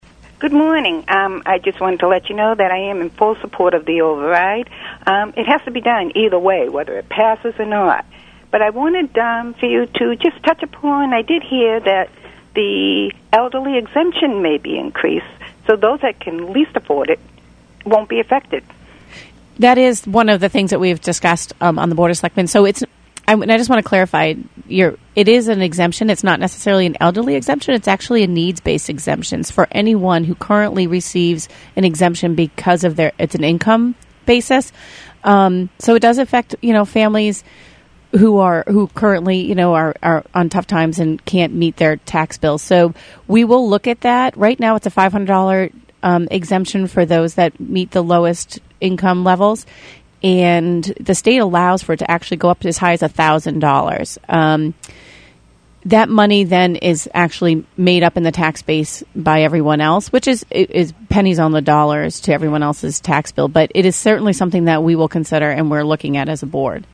A caller
radio show